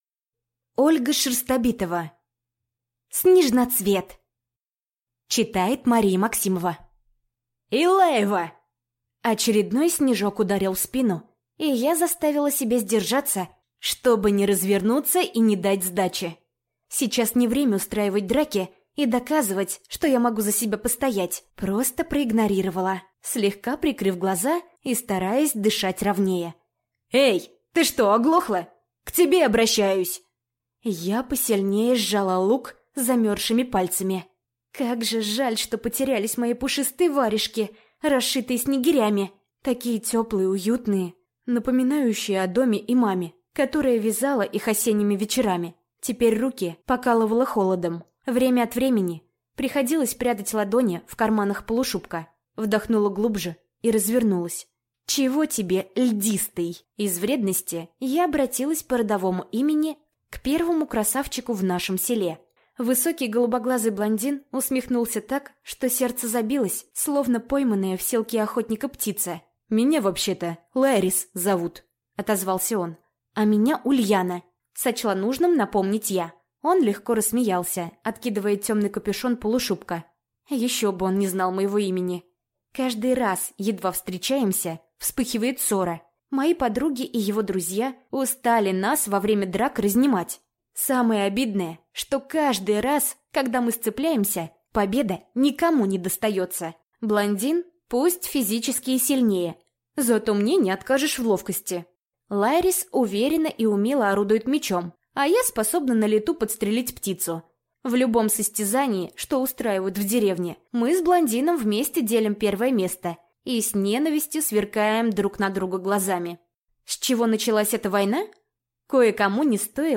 Аудиокнига Снежноцвет | Библиотека аудиокниг
Прослушать и бесплатно скачать фрагмент аудиокниги